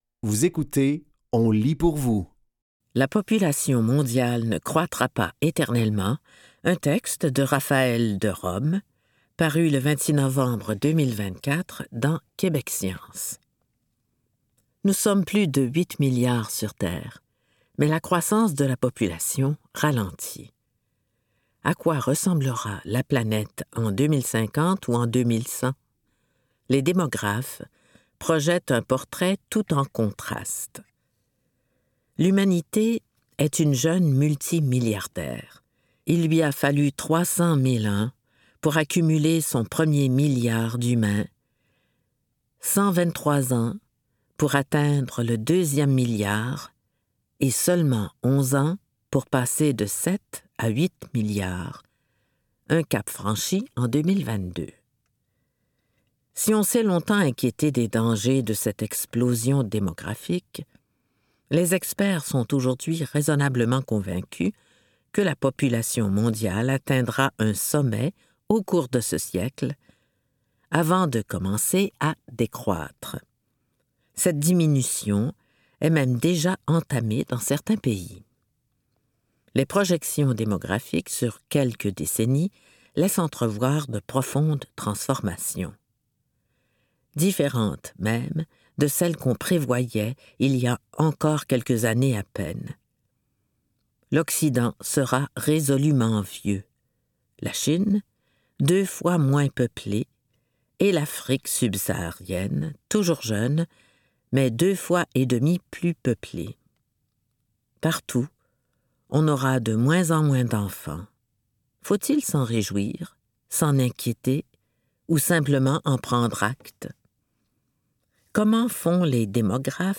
Dans cet épisode de On lit pour vous, nous vous offrons une sélection de textes tirés des médias suivants: Québec Science et Le Devoir.